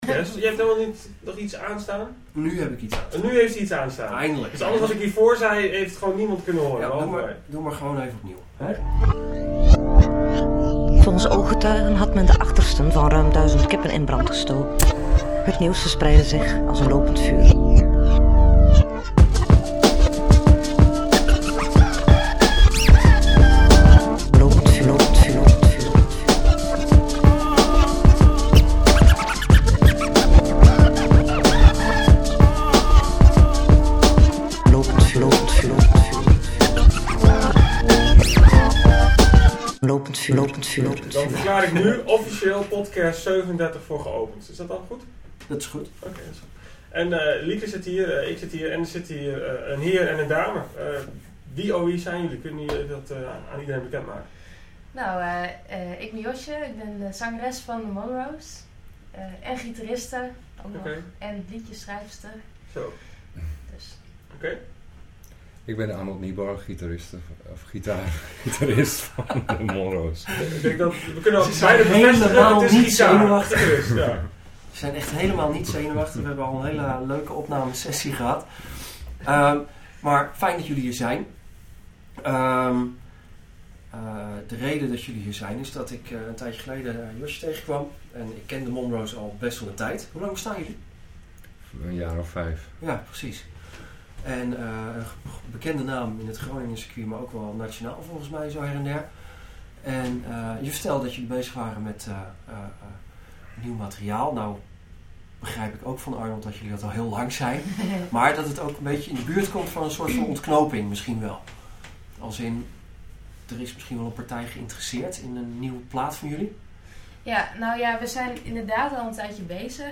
> Play Now | Play in Popup | Download In Podcast 37 hebben we The Monroes op de bank. We praten met ze over hun aanstaande -eerste- cd, de weg naar een platenlabel, spelen in Frankrijk en de ontwikkeling van hun muzikale stijl. In de podcast twee nieuwe nummers, en een cover.